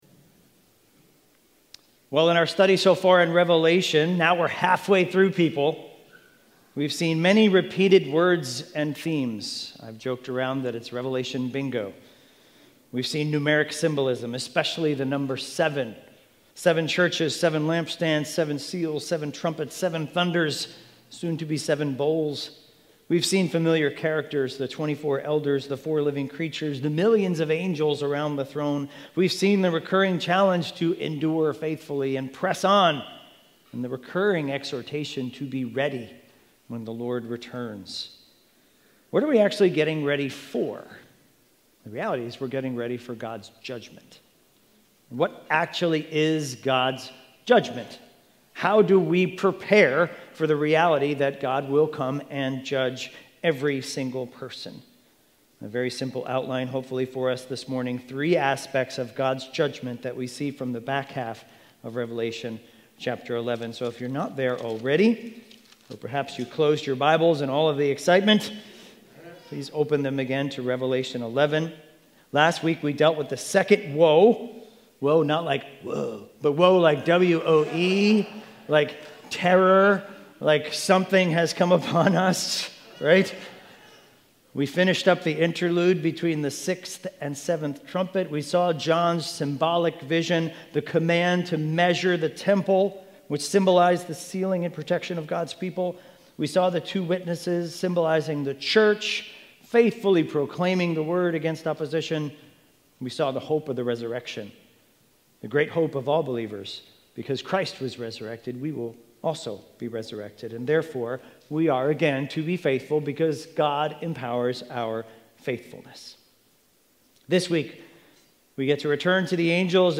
Expositional preaching series through the book of Revelation.